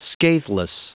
Prononciation du mot scatheless en anglais (fichier audio)
Prononciation du mot : scatheless